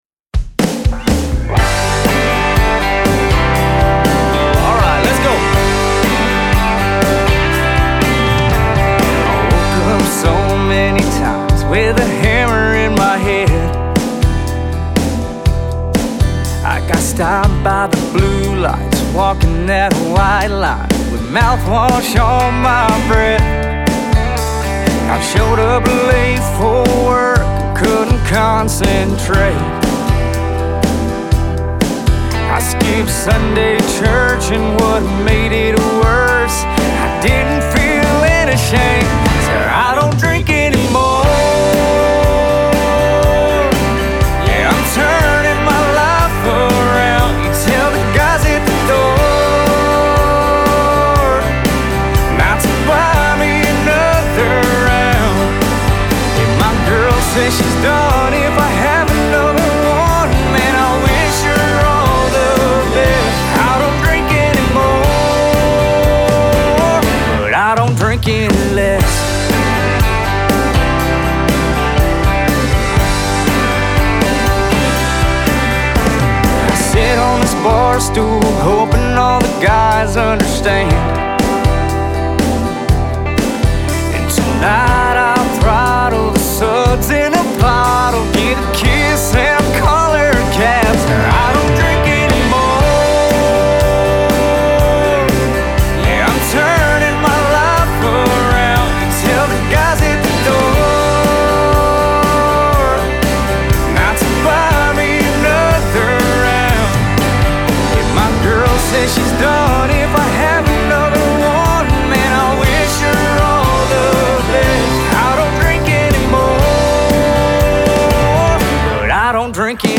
fun, upbeat jam